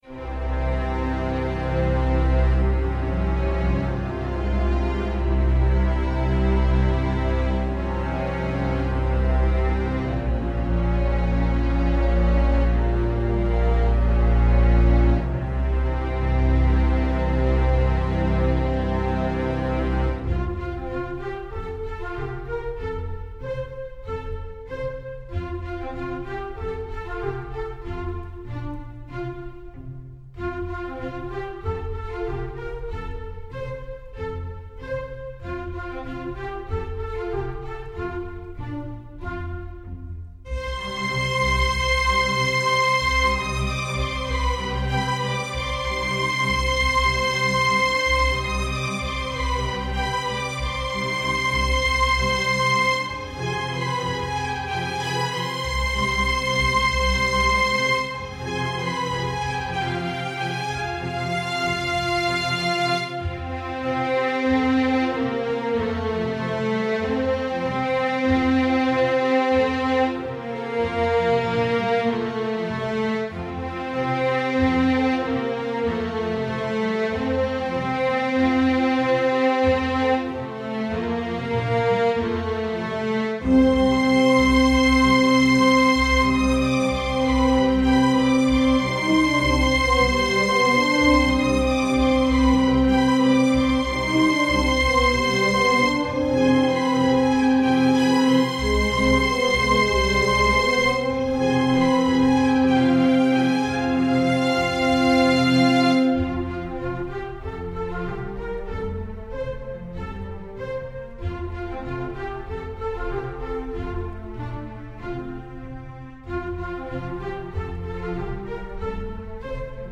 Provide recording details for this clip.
These files are for you to hear what the various accompanying forces sound like and for your practice and memorization.